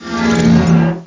ENGINE01.mp3